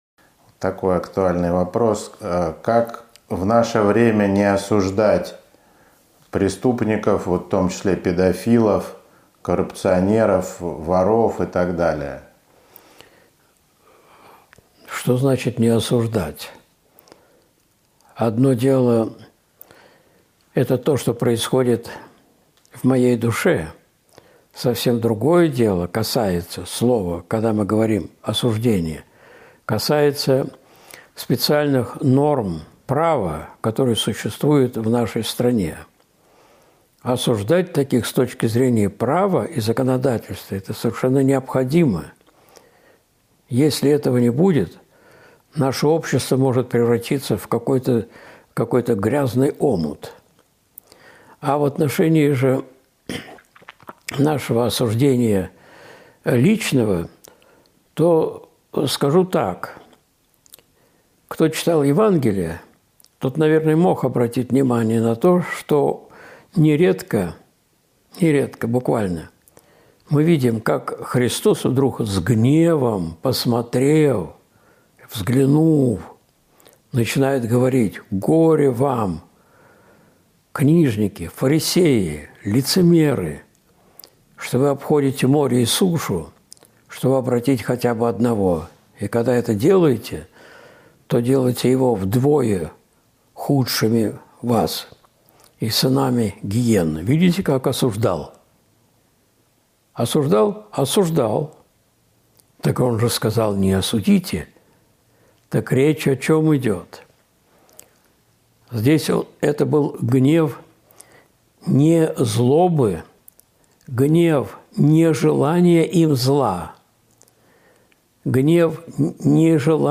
С Богом ничего не страшно! Часть 2 (Ответы на вопросы, 05.10.2023)